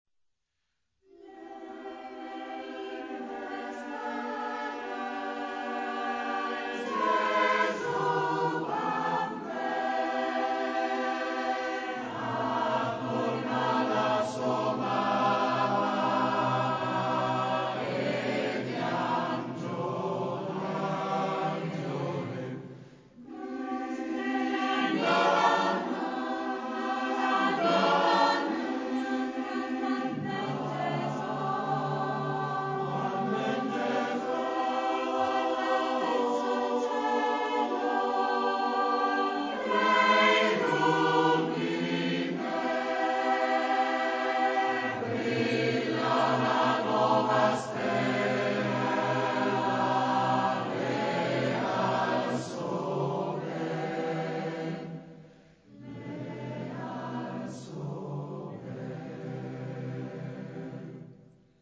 Ricerca, elaborazione, esecuzione di canti popolari emiliani